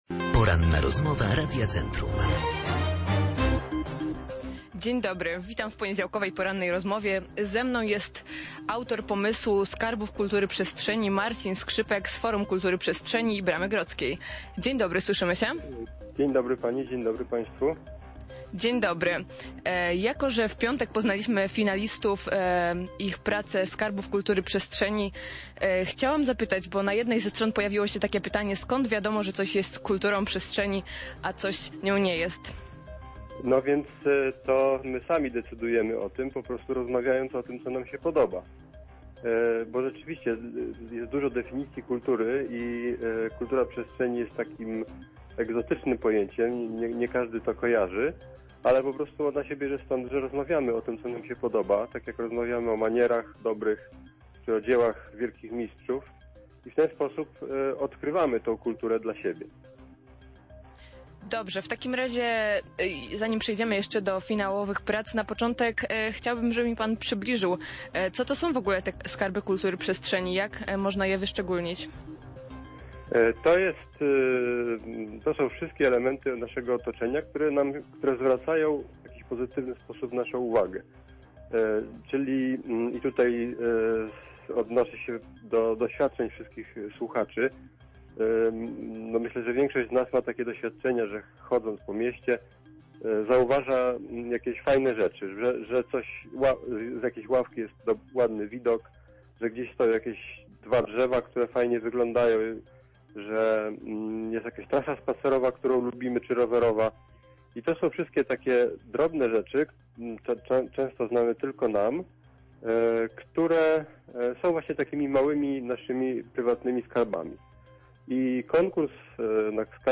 Cała rozmowa